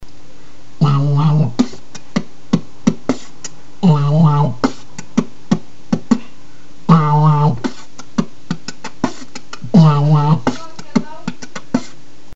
вот качество получше